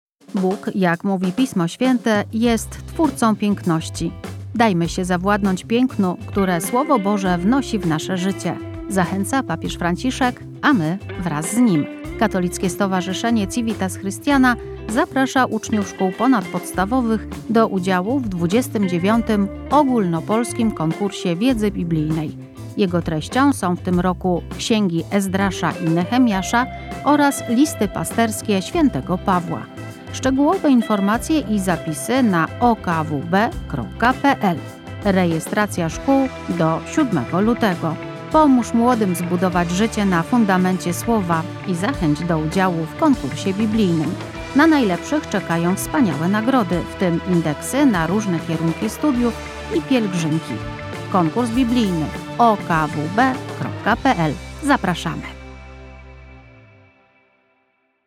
Zapowiedź radiowa dotycząca 29. edycji Ogólnopolskiego Konkursu Wiedzy Biblijnej organizowanego przez Katolickie Stowarzyszenie „Civitas Christiana”.
Komunikat przygotowany przez Radio Jasna Góra